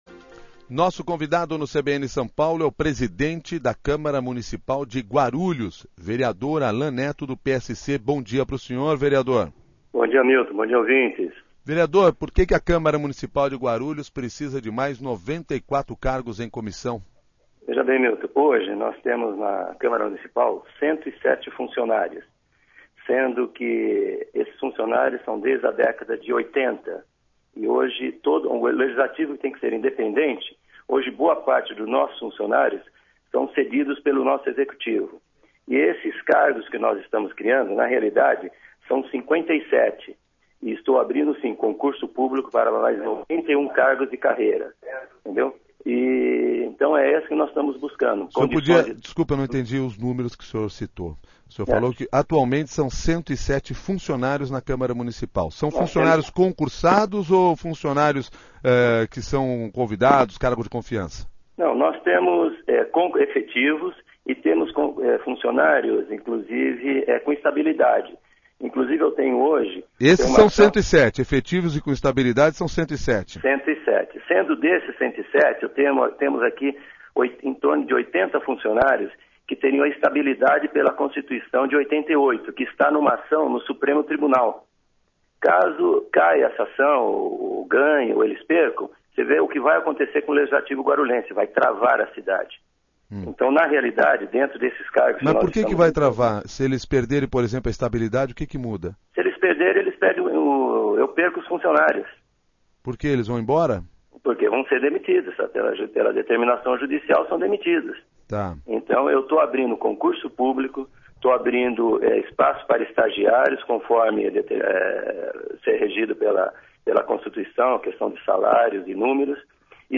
Ouça a entrevista com o presidente da Câmara Municipal de Guarulhos, Alan Neto (PSC)
O presidente da Câmara Municipal de Guarulhos, Alan Neto (PSC), tentou explicar o projeto de lei que prevê aumento de cargos de confiança e vagas para concursados, em entrevista ao CBN São Paulo.